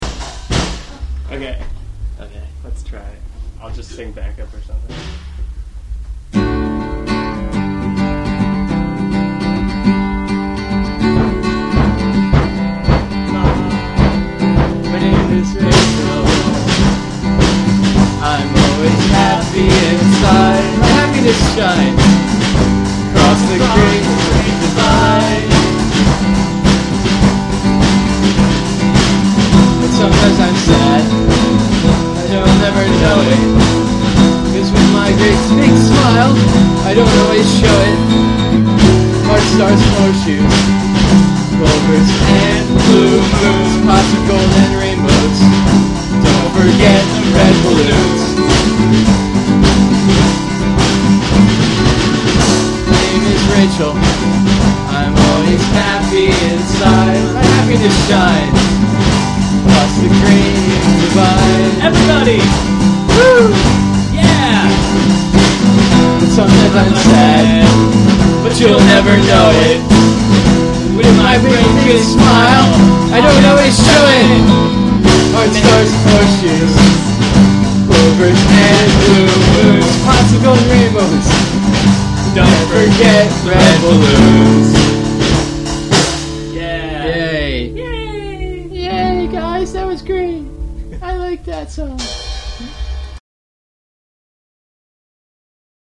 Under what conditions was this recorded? a decent little album featuring a number of fourtrack songs.